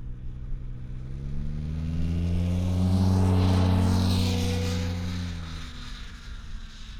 Internal Combustion Snowmobile Description Form (PDF)
Internal Combustion Subjective Noise Event Audio File (WAV)